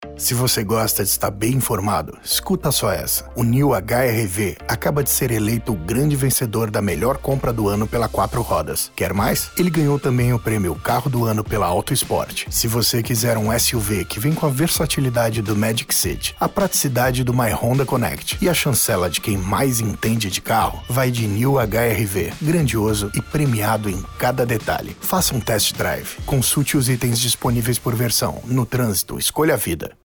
Automotivo
Tenho um home studio de nível profissional.
Jovem adulto